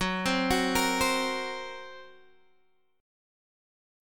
Listen to F#Mb5 strummed